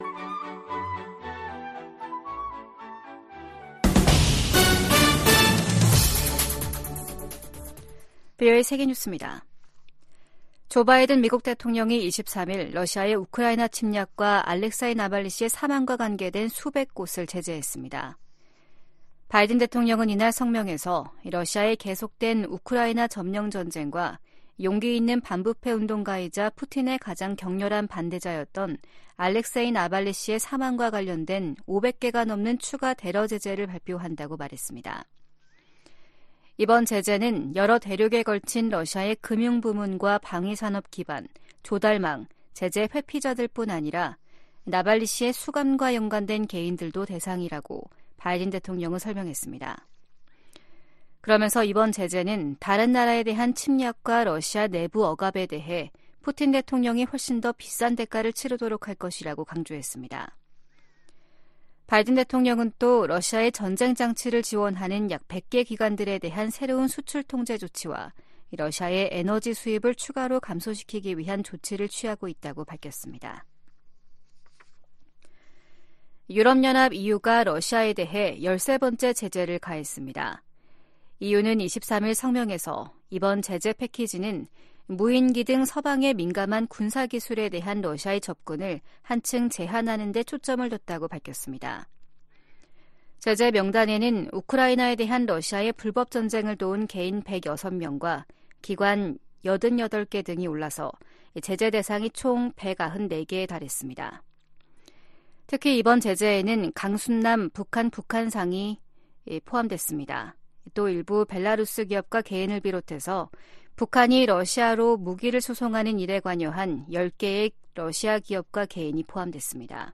VOA 한국어 아침 뉴스 프로그램 '워싱턴 뉴스 광장' 2024년 2월 24일 방송입니다. 미국·한국·일본 외교 수장들이 리우데자네이루 주요20개국(G20) 외교장관회의 현장에서 역내 도전 대응 방안을 논의했습니다. 보니 젠킨스 미 국무부 군비통제·국제안보 차관이 북한-러시아의 군사 협력을 심각한 우려이자 심각한 위협으로 규정했습니다. 빅토리아 눌런드 국무부 정무차관은 러시아가 포탄을 얻는 대가로 북한에 어떤 기술을 넘겼을지 누가 알겠느냐며 우려했습니다.